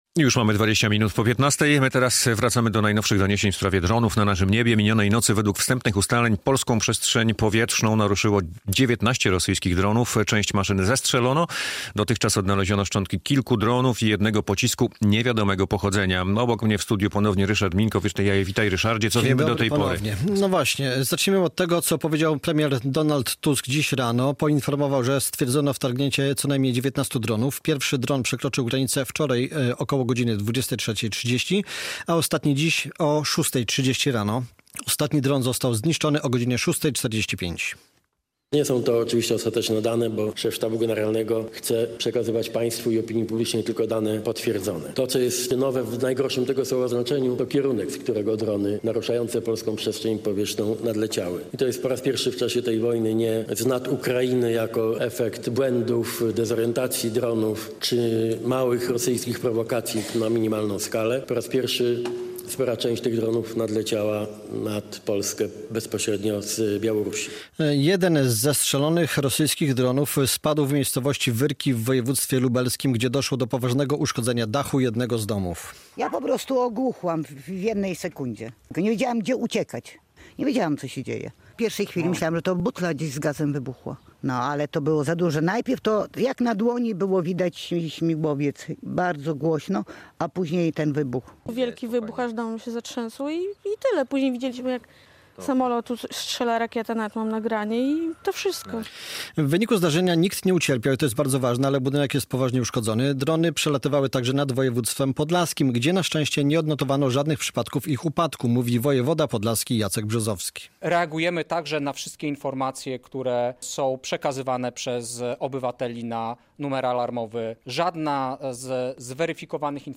Rosyjskie drony nad Polską - relacja